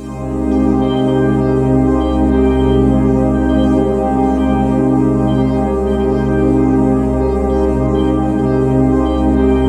Index of /90_sSampleCDs/USB Soundscan vol.13 - Ethereal Atmosphere [AKAI] 1CD/Partition A/08-SEQ PAD A
SEQ PAD02.-R.wav